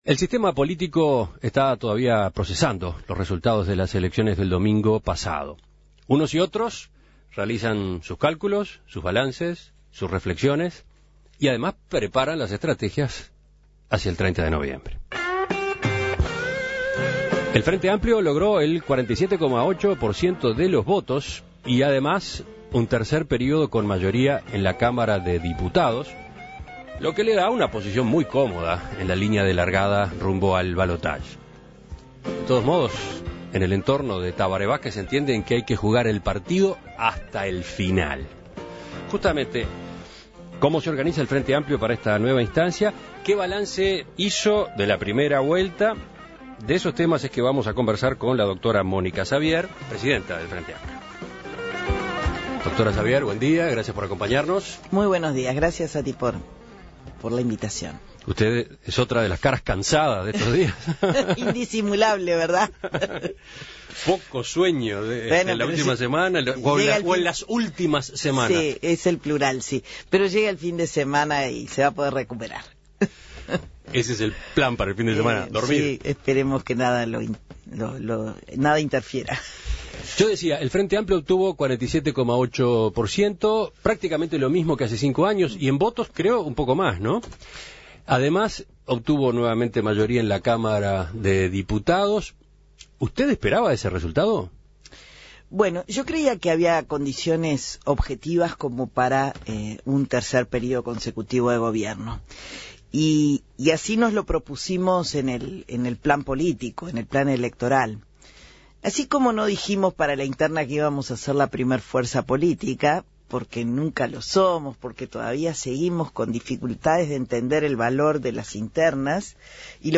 En Perspectiva recibió en estudios a la presidente del FA, Mónica Xavier, para conversar sobre estos y otros temas de campaña.